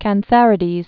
(kăn-thărĭ-dēz)